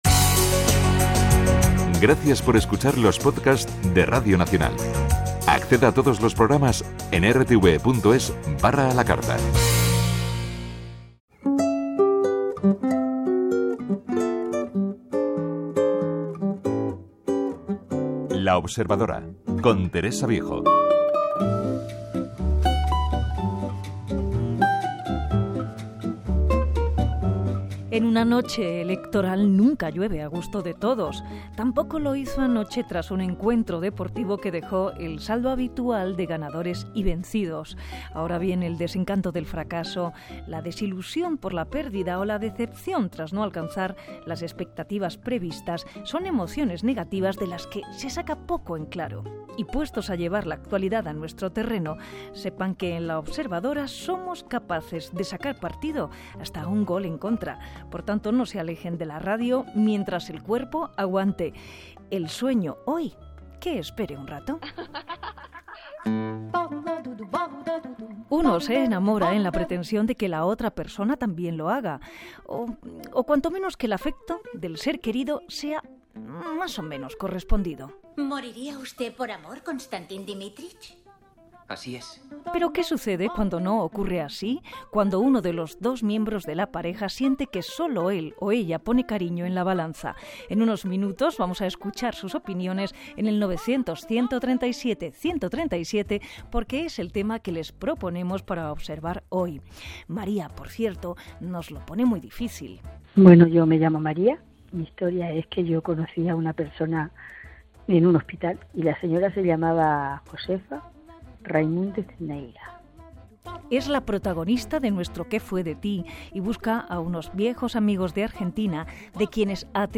Estuvimos hablando de lo que ocurre cuando uno es el que ama más que otro, y también entraron llamadas de algunos oyentes.